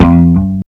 Bass (15).wav